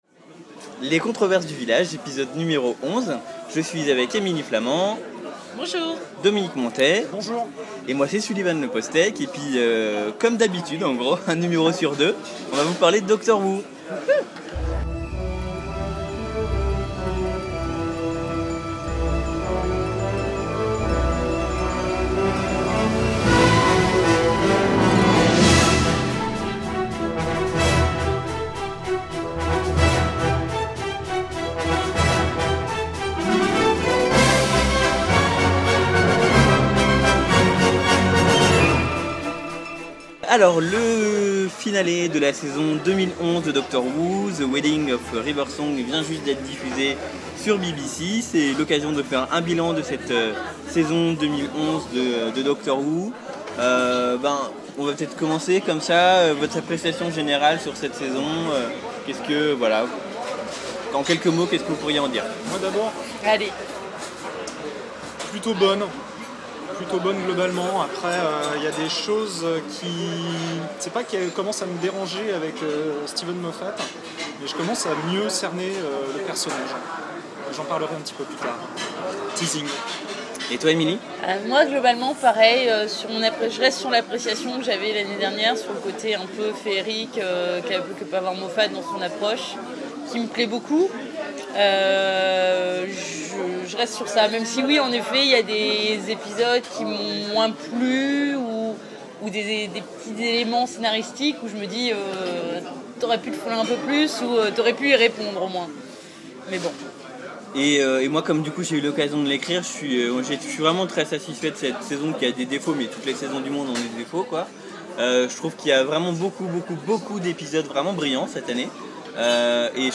Au tour de la rédaction du Village de faire son bilan de la saison : Le podcast (Le bruit d’ambiance est un peu fort dans ce numéro. on essaiera de faire mieux à l’avenir.)